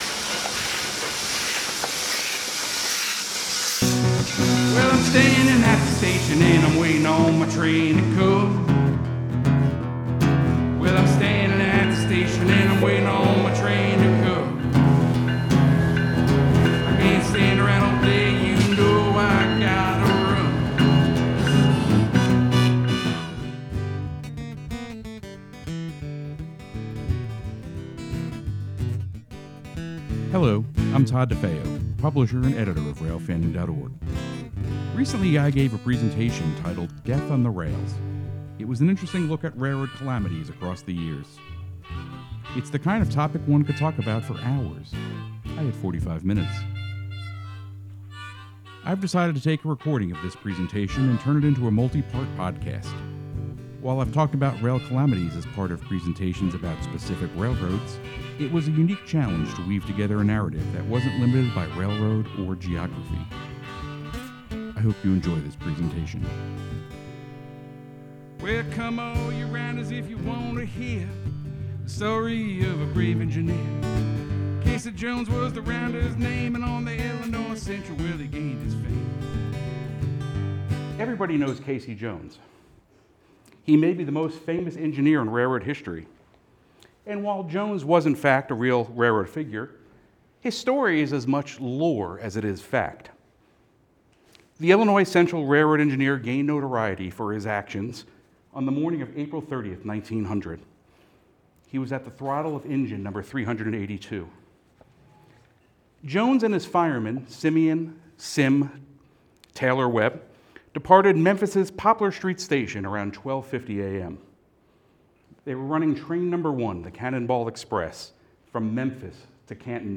I’ve decided to take a recording of this presentation and turn it into a multi-part podcast.